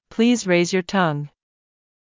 ﾌﾟﾘｰｽﾞ ﾚｲｽﾞ ﾕｱ ﾀﾝｸﾞ
英語ではタンと発音します。